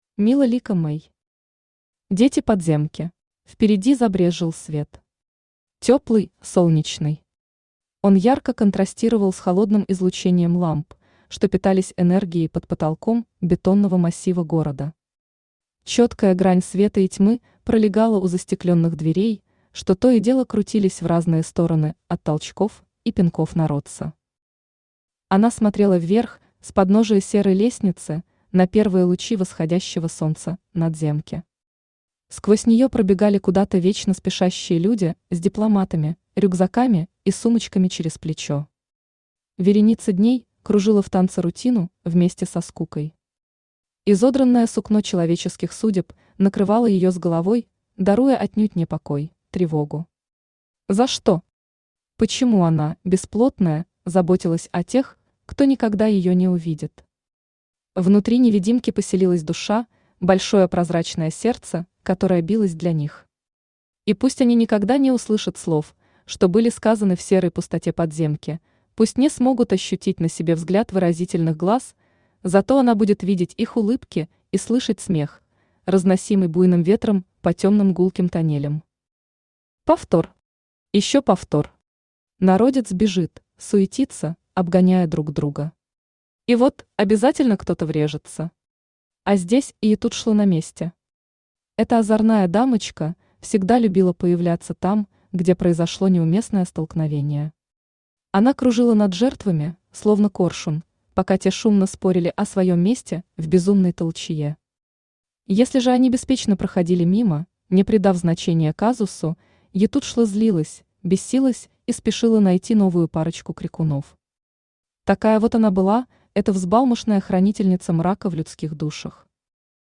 Аудиокнига Дети Подземки | Библиотека аудиокниг
Aудиокнига Дети Подземки Автор МилаЛика Мэй Читает аудиокнигу Авточтец ЛитРес.